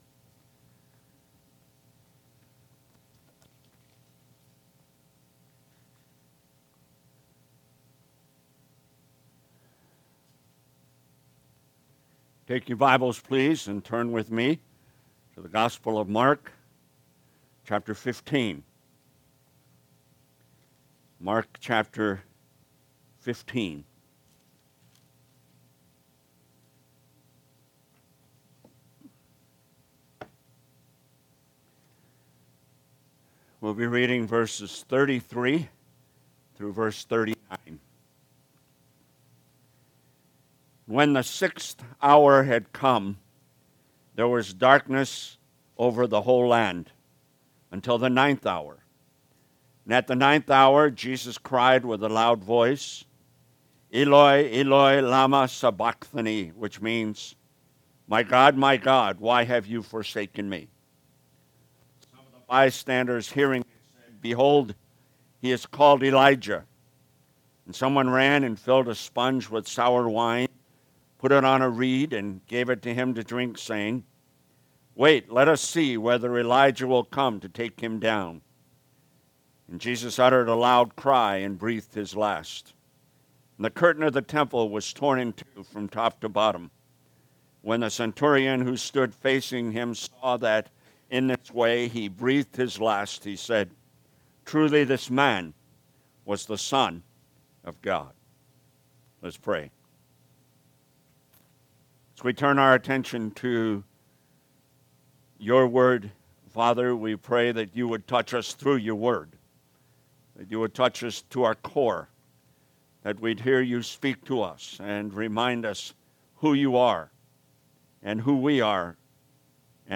Sermons - Pleasant Prairie Baptist Church